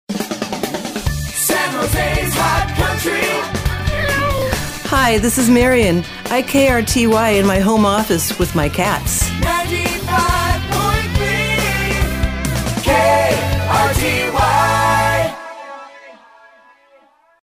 (I have this nice microphone from work as I make a lot of training videos).